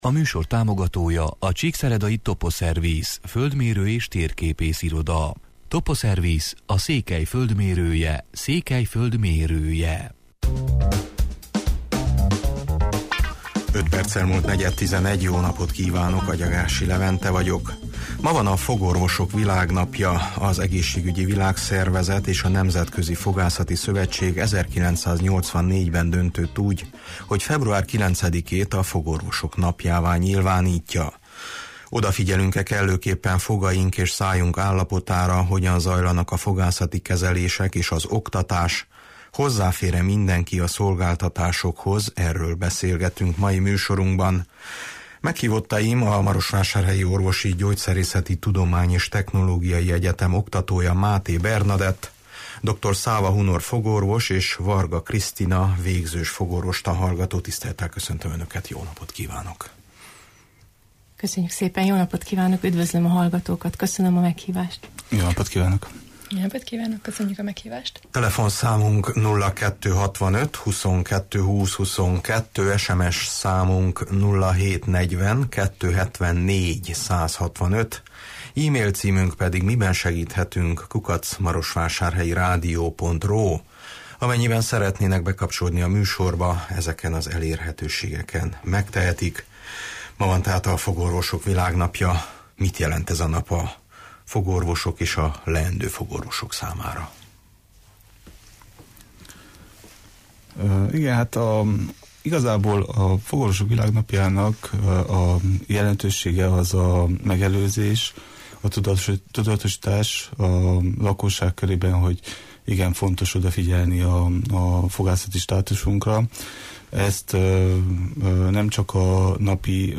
Odafigyelünk-e kellőképpen fogaink és szájunk állapotára, hogyan zajlanak a fogászati kezelések, hozzáfér-e mindenki a szolgáltatásokhoz – erről beszélgetünk mai műsorunkban.